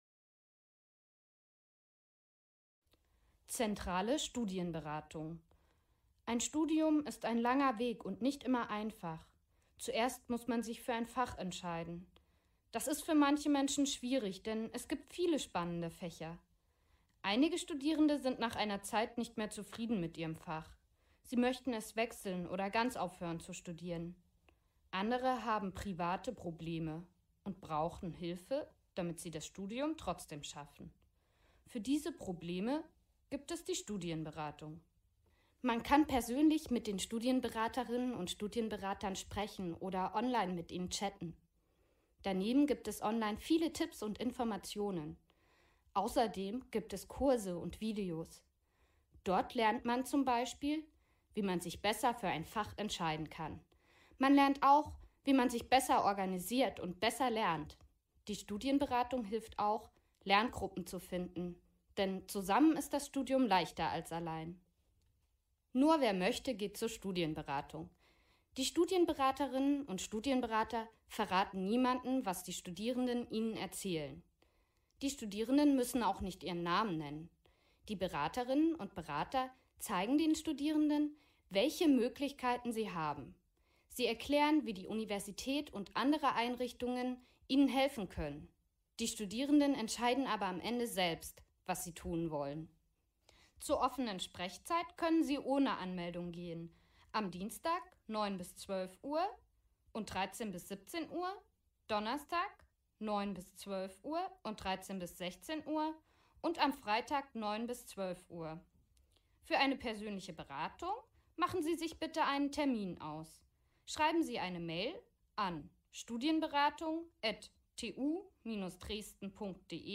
Hörversion der Seite.